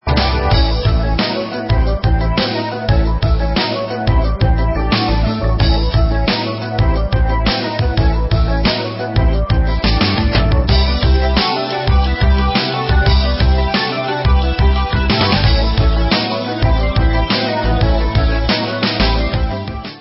soothing song
sledovat novinky v oddělení Rock/Progressive